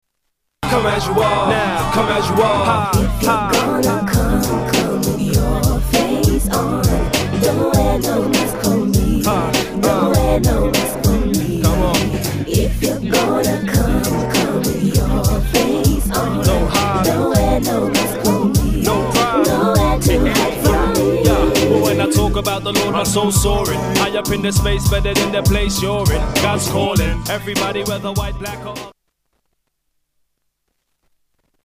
STYLE: Hip-Hop
with a good sung hook